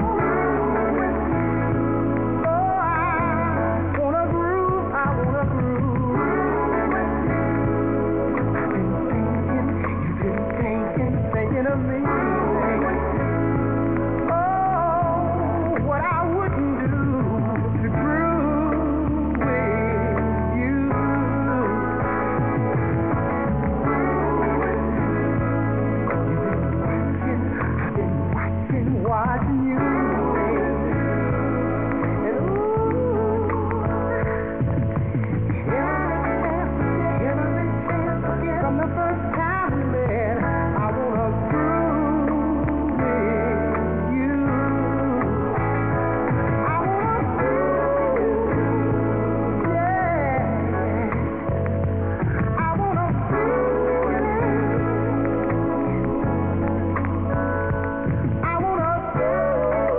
70's/DISCO